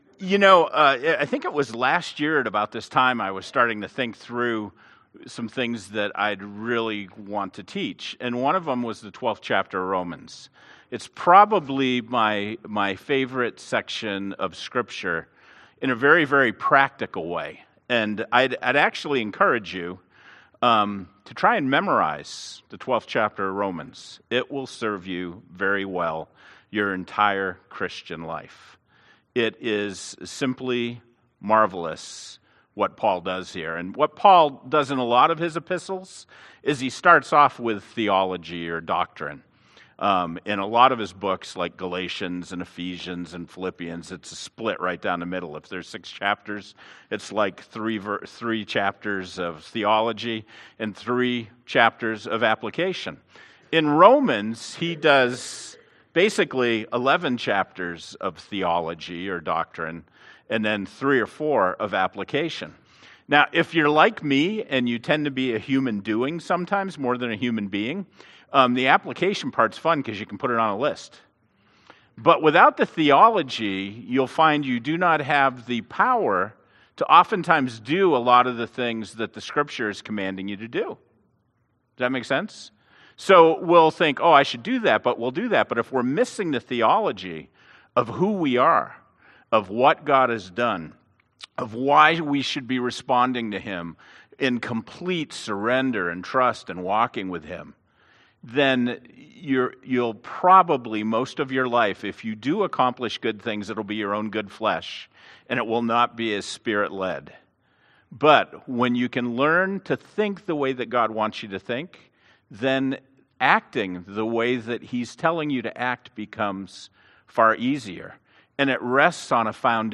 1-06-19-Sermon.mp3